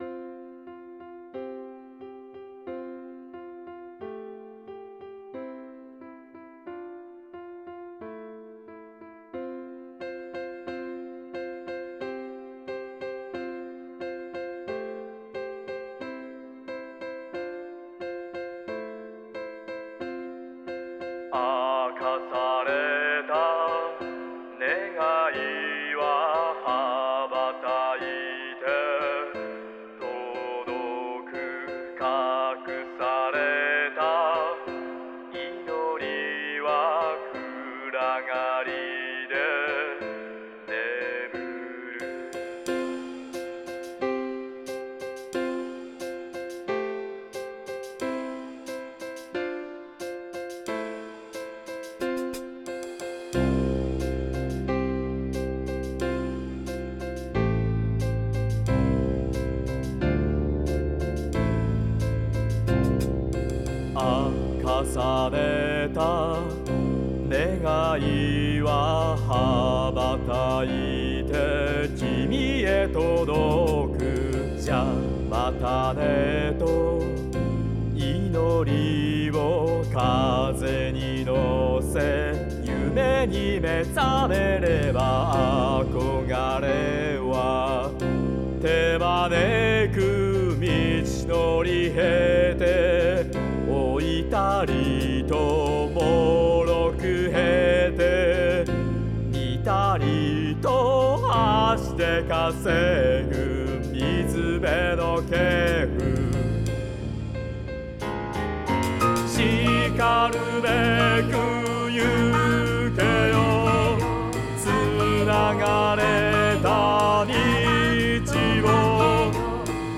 000 秒 概要 FLB初のOn-Vocal曲。